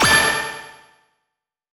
snd_undertale_flash.wav